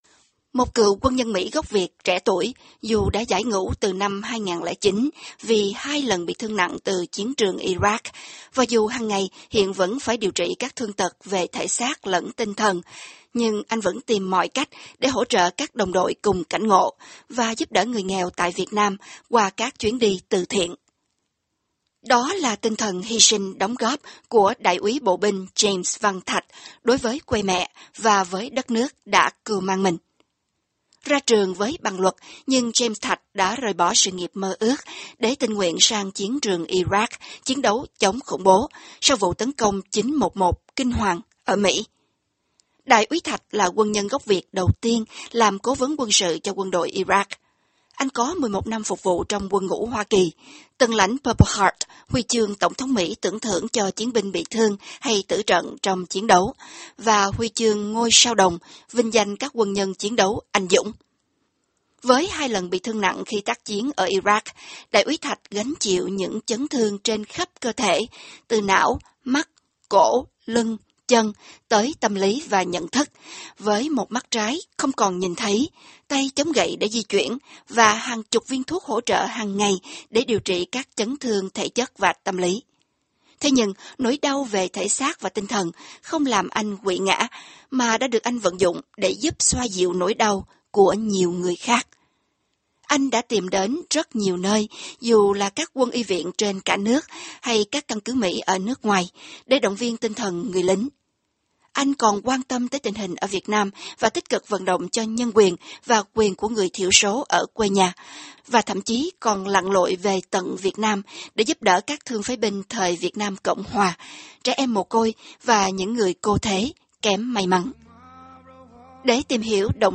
trong câu chuyện với Tạp chí Thanh Niên VOA hôm nay.